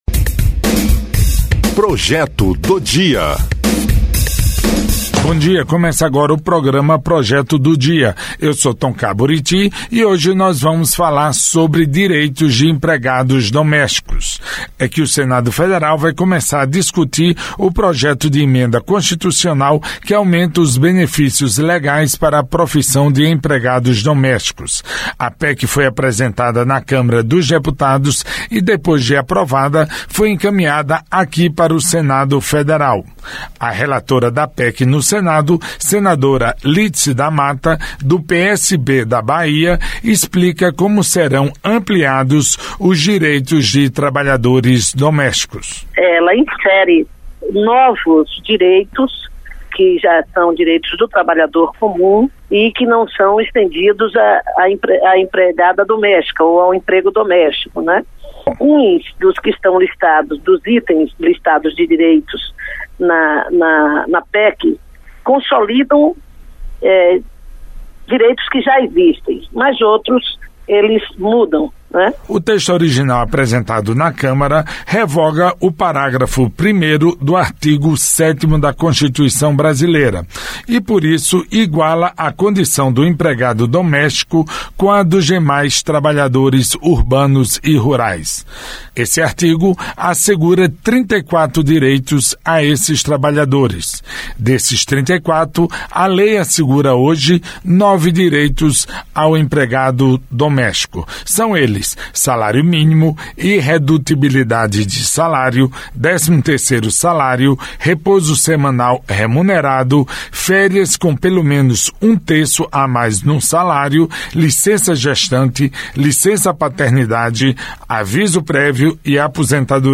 Entrevista com a senadora Lídice da Mata (PSB-BA).